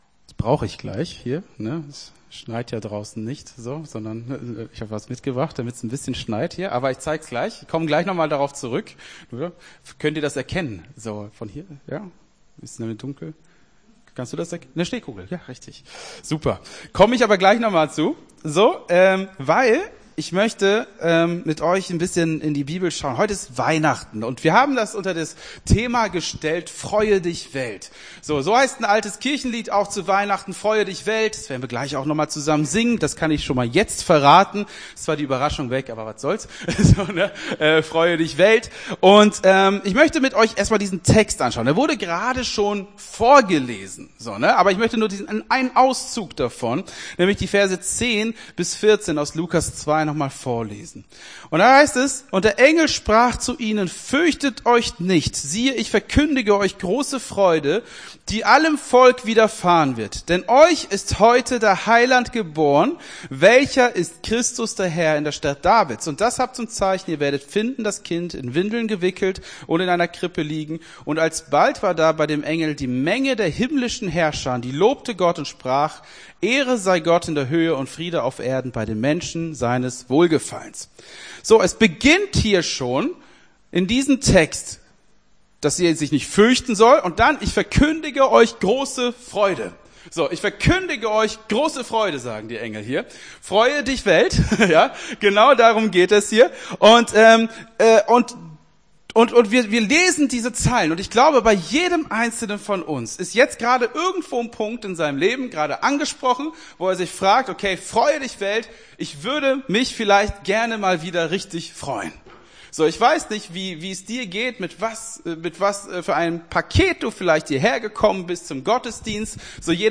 Gottesdienst 24.12.23 - FCG Hagen